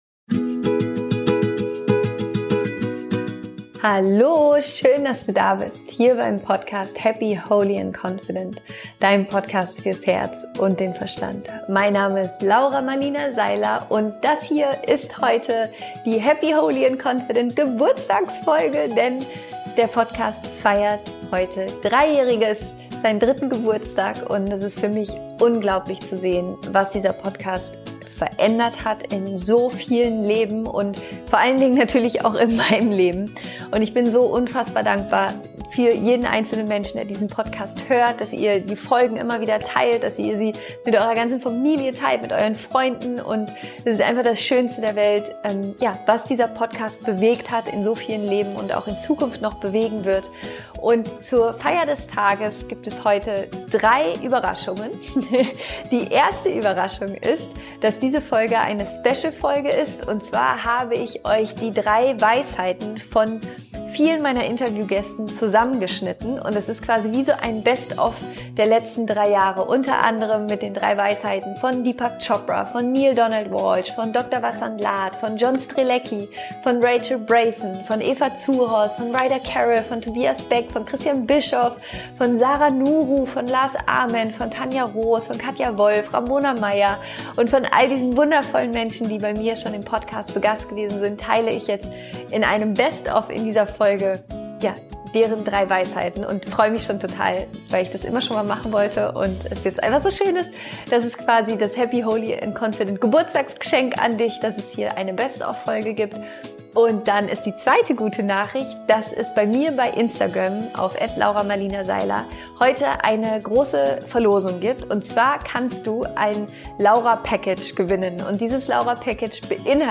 Zur Feier des Tages gibt es heute eine Best Of Folge der 3 Weisheiten meiner Interview Gäste aus den letzten 3 Jahren.